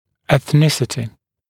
[eθ’nɪsətɪ][эс’нисэти]этническая принадлежность